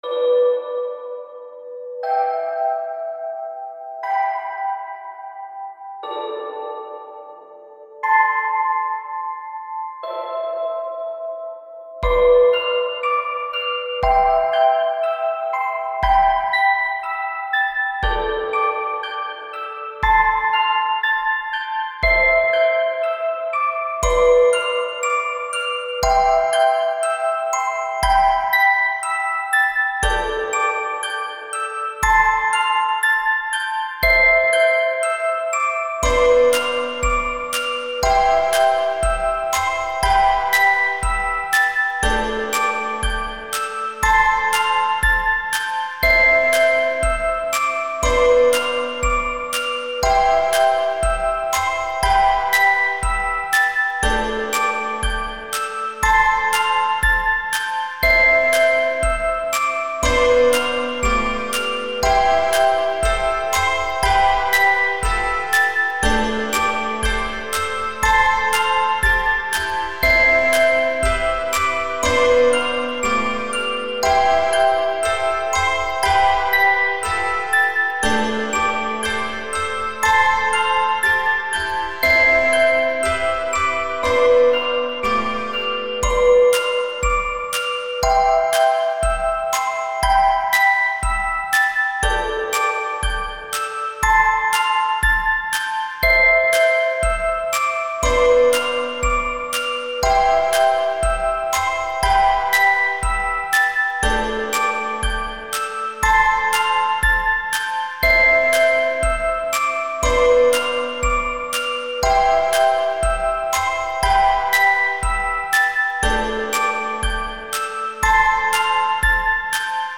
Home > Music > Beats > Bright > Dreamy > Laid Back